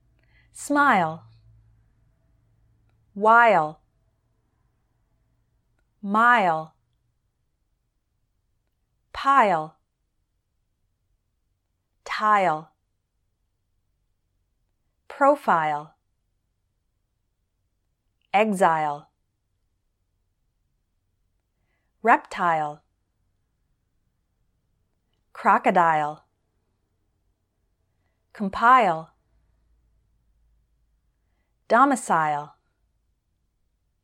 Now I’m from the U.S., so for the rest of this video, I’m going to teach you the way we say these words in American English.